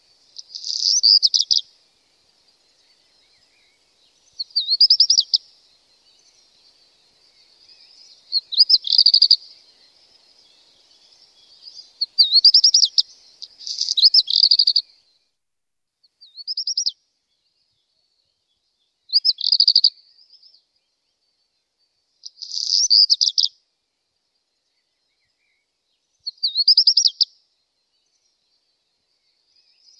rougequeue-noir.mp3